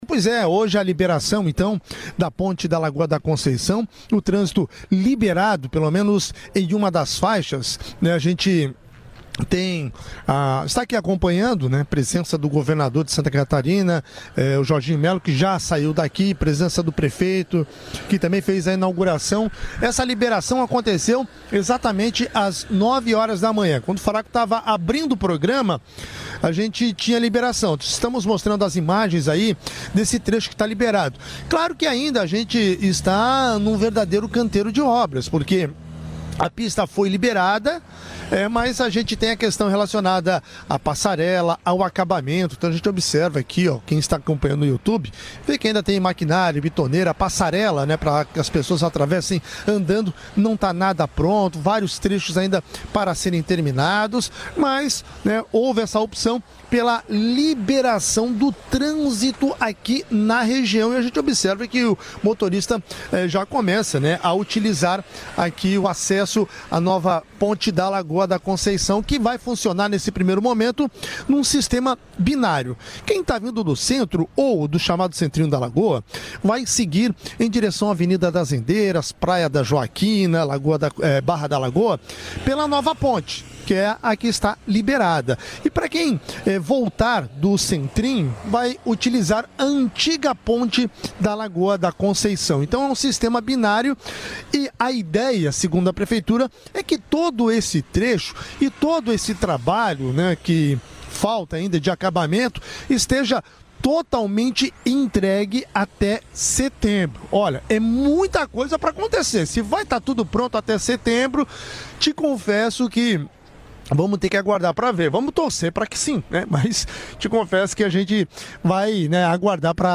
Em entrevista para a CBN Floripa, o prefeito de Florianópolis Topázio Neto, afirmou que a entrega total da nova Ponte da Lagoa da Conceição está previsto para setembro.